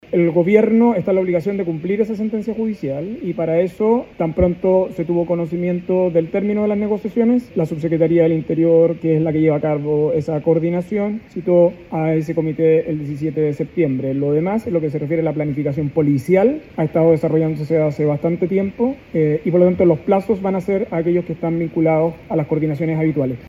Sobre las coordinaciones que ya se realizan con las autoridades y el contingente policial se refirió el ministro de Seguridad Pública, Luis Cordero, precisando que el Gobierno está en la obligación de cumplir la sentencia judicial.